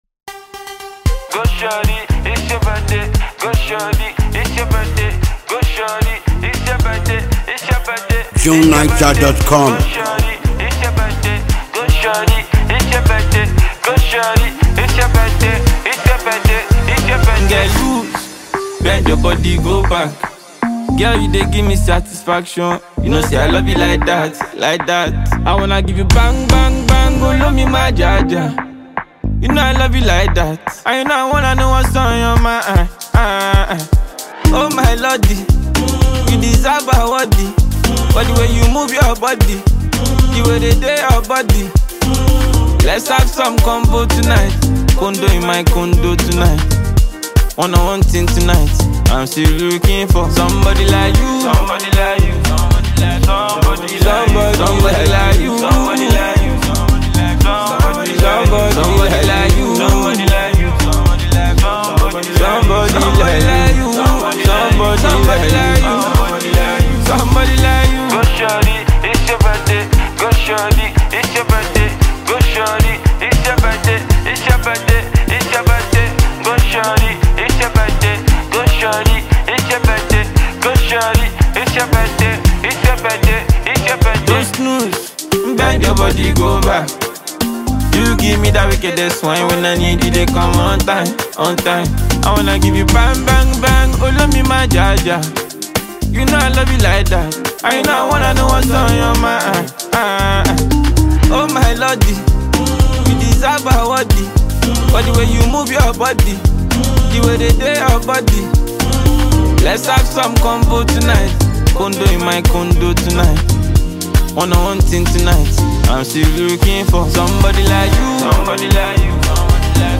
This cheerful record